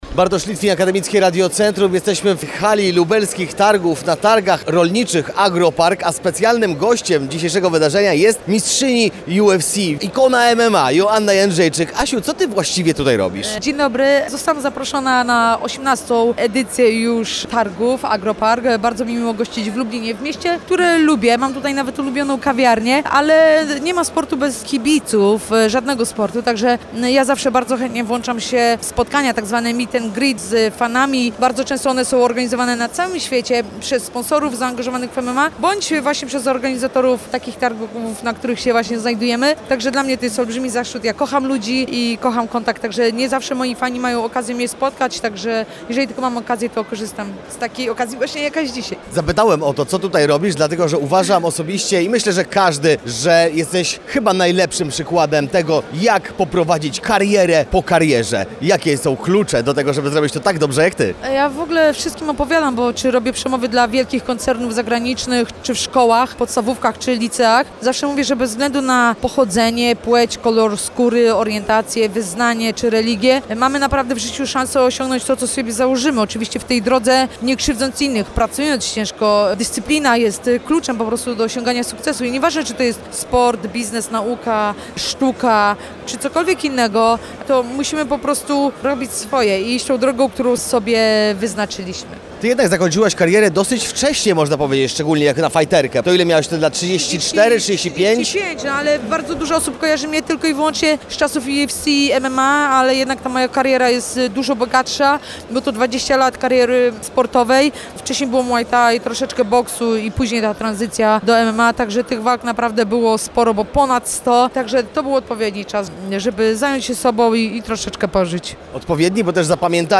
Tę fenomenalną zawodniczkę spotkaliśmy podczas lubelskich Targów Rolniczych AGRO-PARK.
Joanna-Jedrzejczyk-rozmowa.mp3